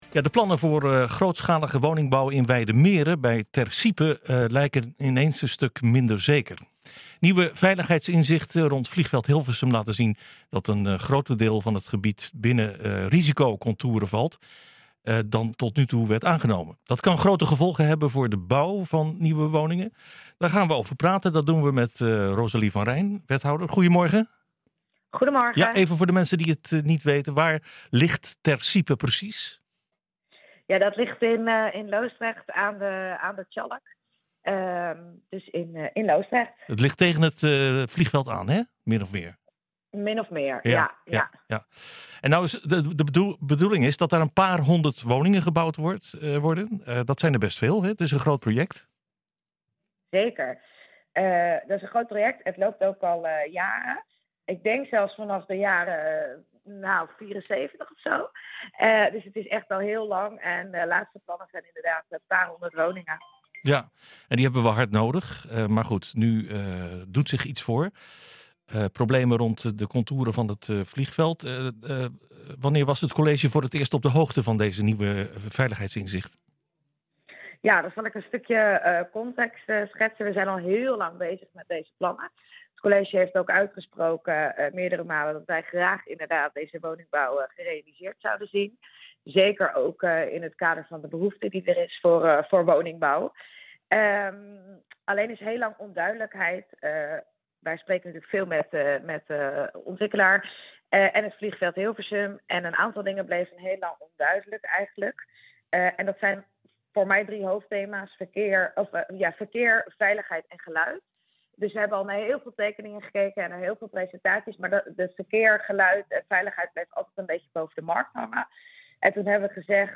Wij spreken hierover met wethouder Rosalie van Rijn.